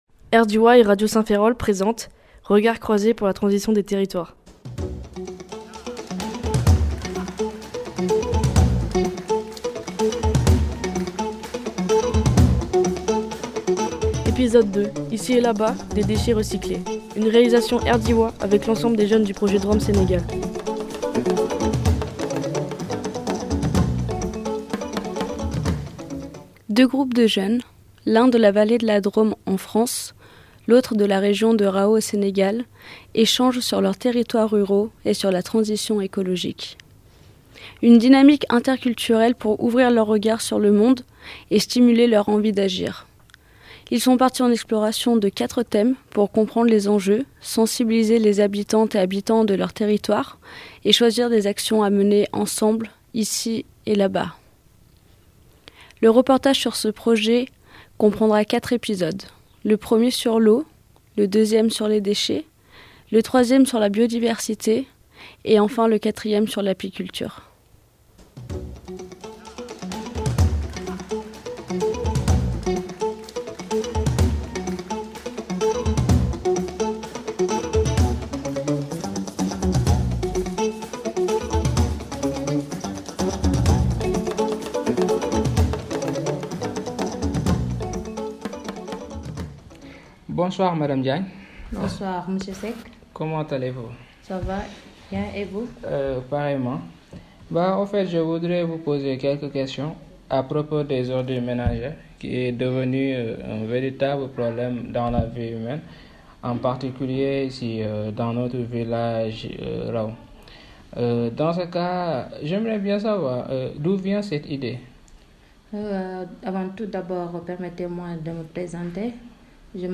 Ils échangent sur leurs territoires ruraux et sur les enjeux de transition écologique, en bénéficiant de toute la richesse de l’interculturalité, pour mener des actions sur chacun des territoires. Ces jeunes offrent à vos oreilles leurs regards croisés sur quatre thèmes : l’eau, les déchets, la biodiversité et l’apiculture…